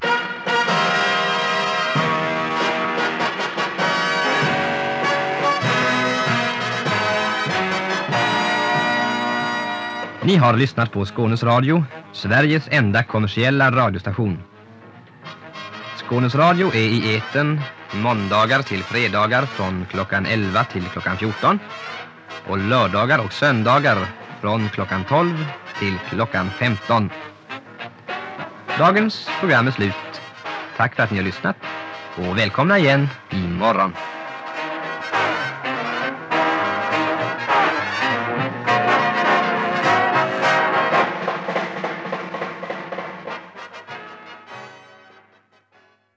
‘Daily closedown announcement